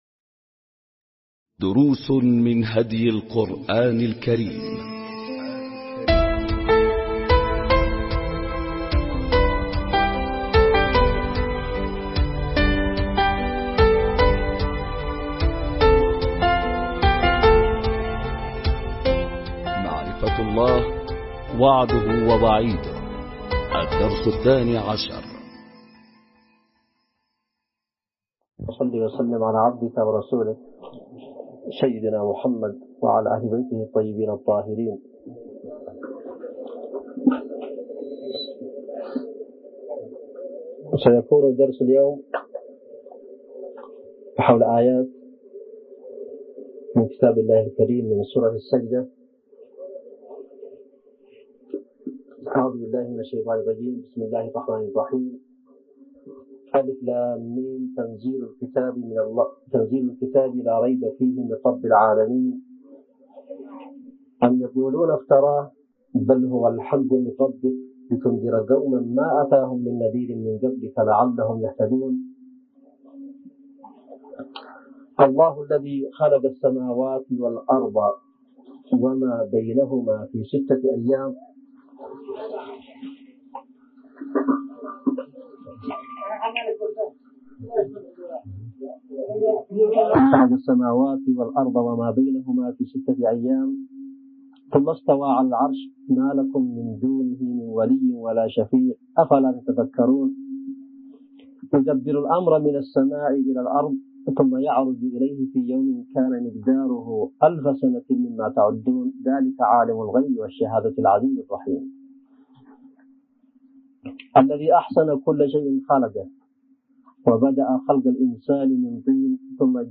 دروس من هدي القرآن الكريم معرفة الله – وعده ووعيده – الدرس الثاني عشر ملزمة الأسبوع | اليوم الأول ألقاها السيد / حسين بدرالدين الحوثي | مؤسسة الشهيد زيد علي مصلح
اليمن - صعدة